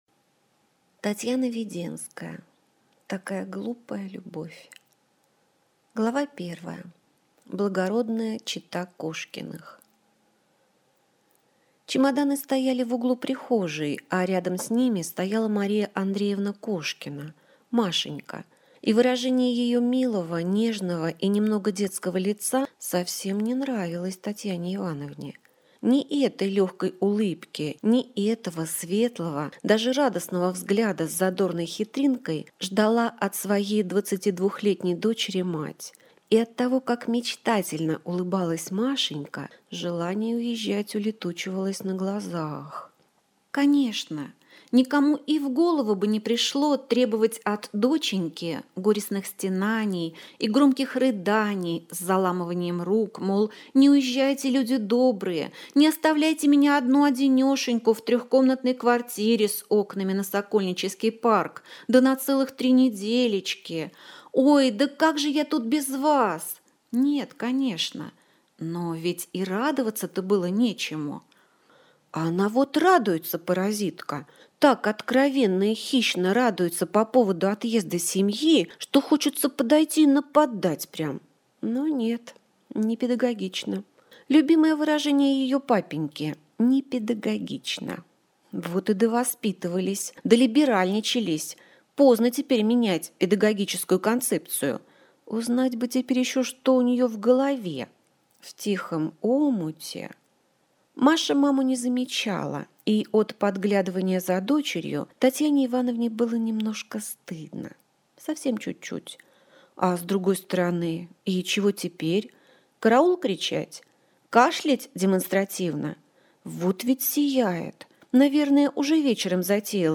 Аудиокнига Такая глупая любовь - купить, скачать и слушать онлайн | КнигоПоиск